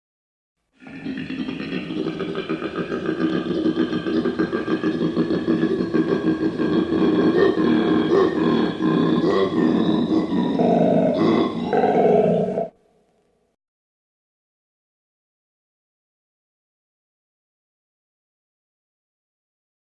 We have two very large gum trees in our front yard, and every so often, they are visited by the local koala.
The first time you are woken at three in the morning by a koala call is not something you forget.